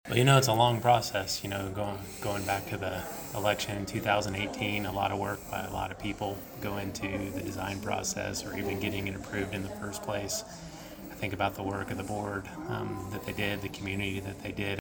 USD 383 Superintendent Eric Reid thanked the contractors, school board members and residents who helped make the addition possible.
USD 383 Superintendent Eric Reid speaking to visitors on Saturday August 5th at Manhattan High School West Campus.